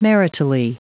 Prononciation du mot maritally en anglais (fichier audio)
Prononciation du mot : maritally